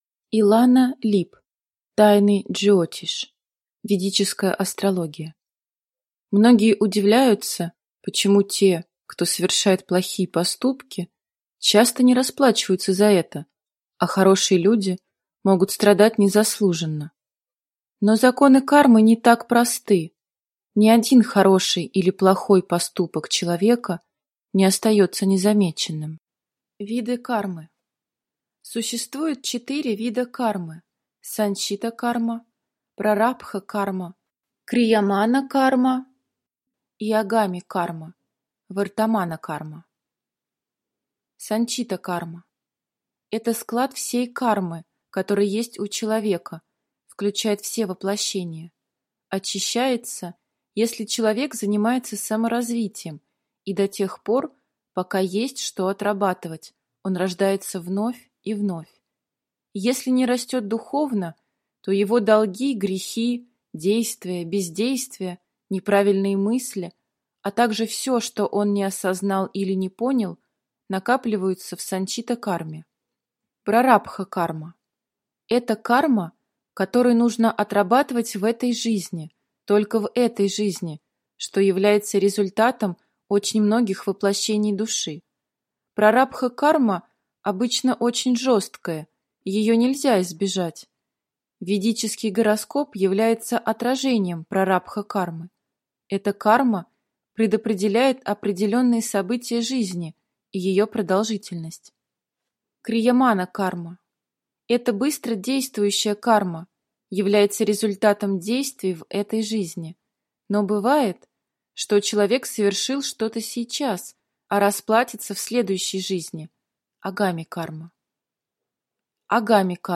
Аудиокнига Тайны Джйотиш. Ведическая астрология | Библиотека аудиокниг